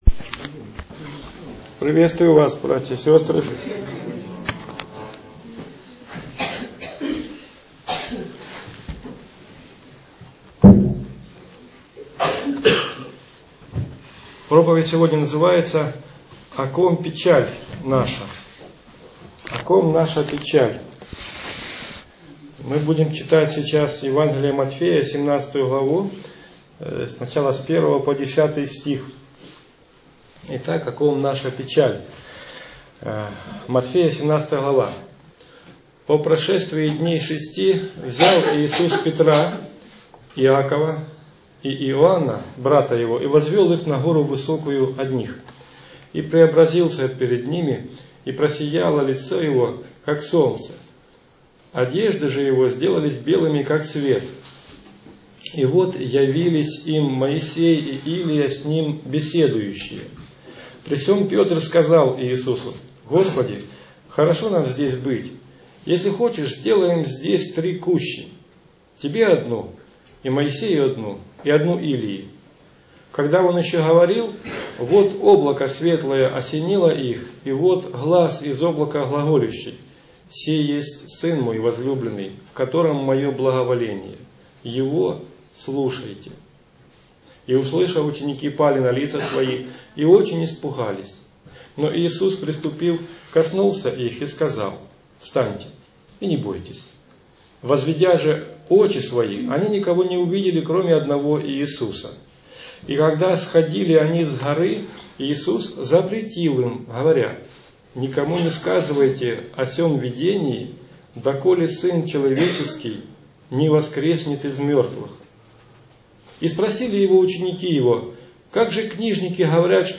Аудио-проповедь.